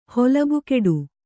♪ holabu keḍu